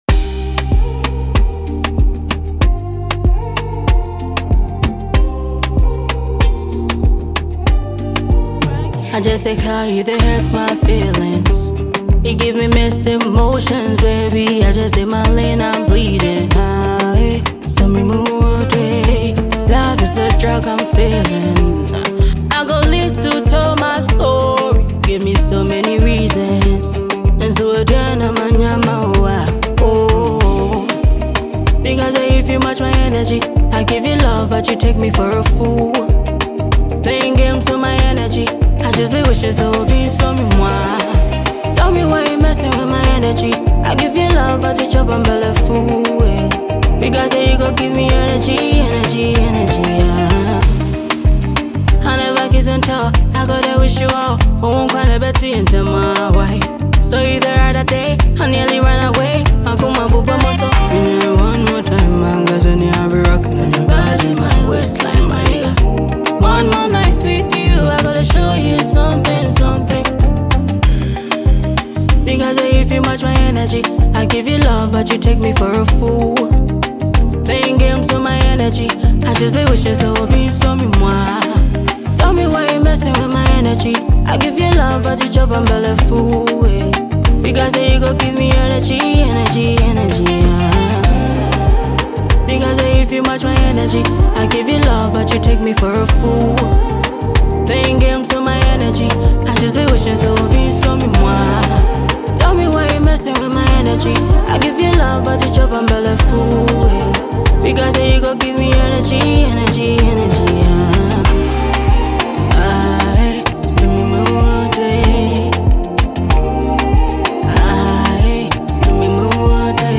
blends smooth melodies with catchy rhythms